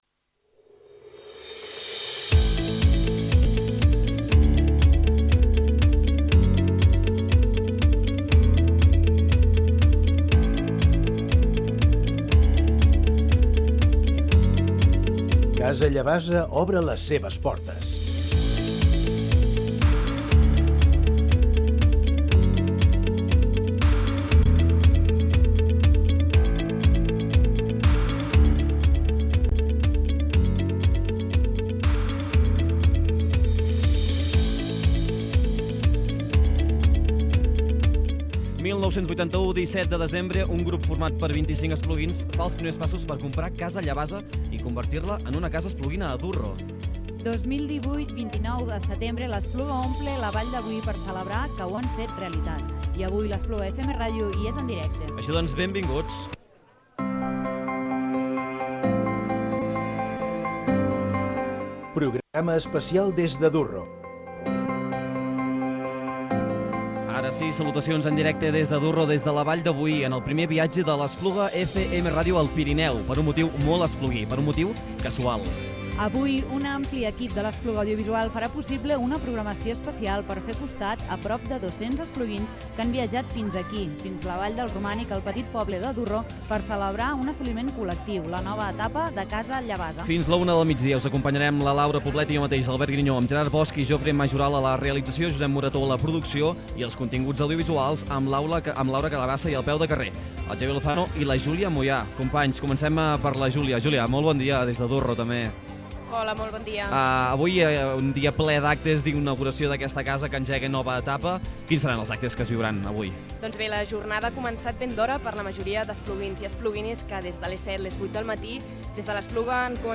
Retransmissió-Acte-Inaugural-Casa-Llavasa-1.mp3